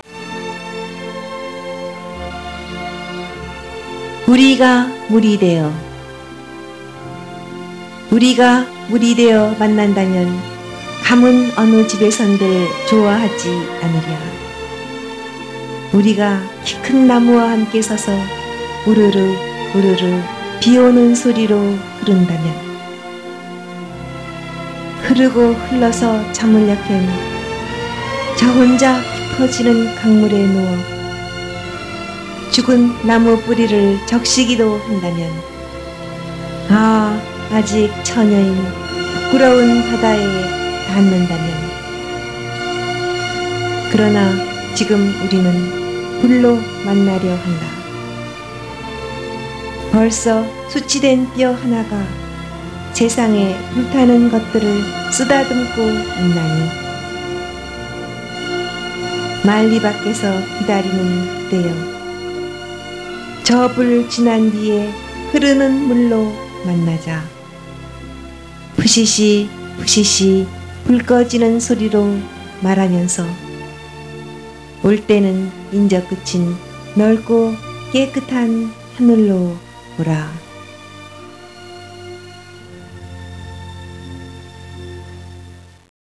강은교 자작시 낭송 파일
<우리가 물이 되어>를 시인이 직접 낭송하였습니다.